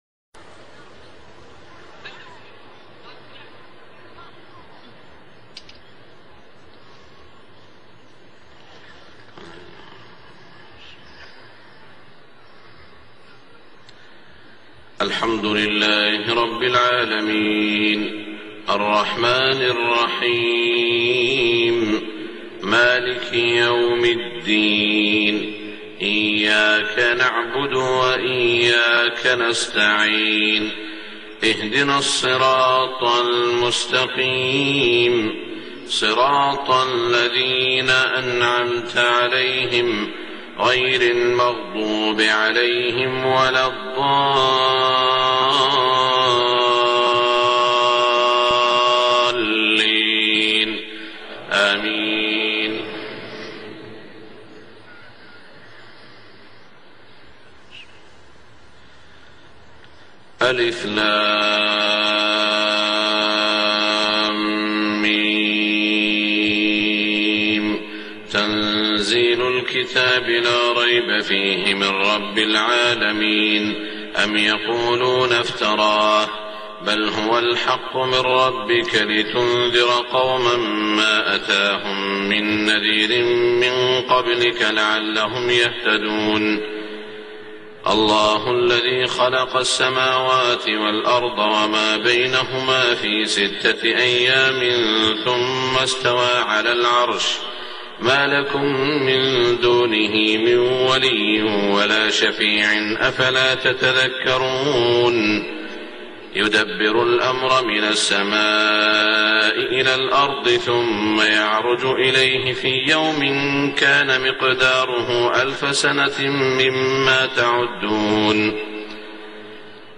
صلاة الفجر 1425 سورة السجدة > 1425 🕋 > الفروض - تلاوات الحرمين